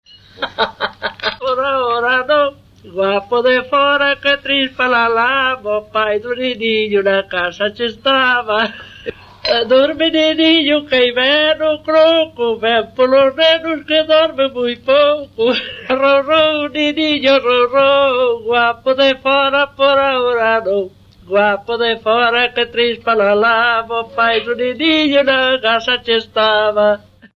Palabras chave: arrolo
Tipo de rexistro: Musical
Lugar de compilación: Lugo - Lugo (San Pedro)
Soporte orixinal: Casete
Instrumentación: Voz
Instrumentos: Voz feminina